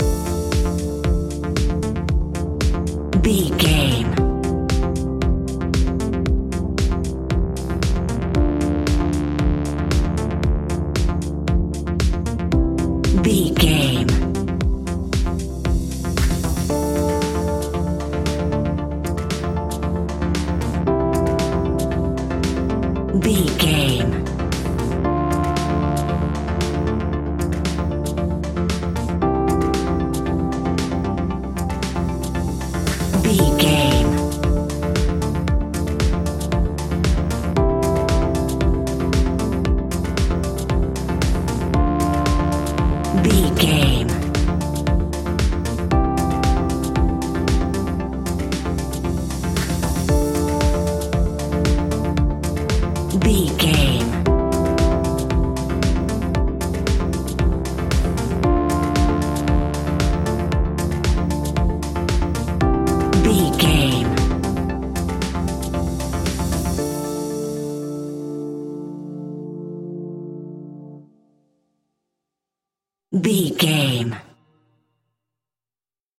Aeolian/Minor
D
funky
groovy
uplifting
driving
energetic
drum machine
electric piano
synthesiser
electro house
funky house
synth leads
synth bass